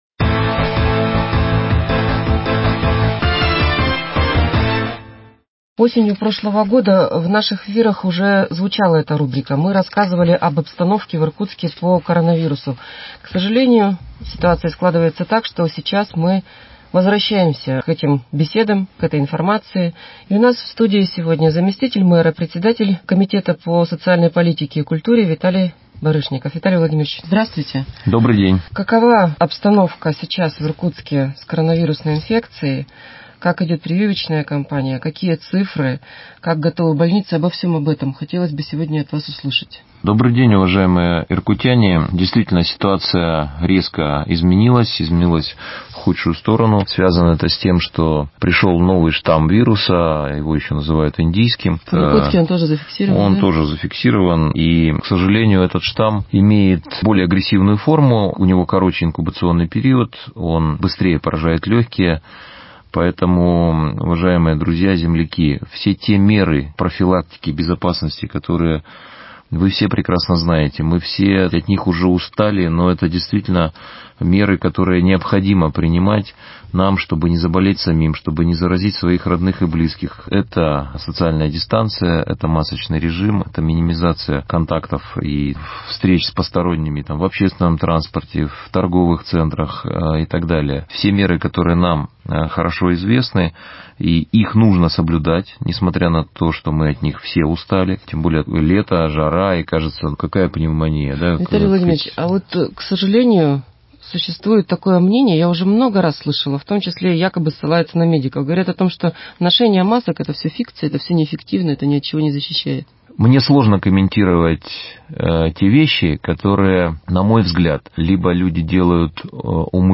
Актуальное интервью: Эпидситуация в Иркутске 22.06.2021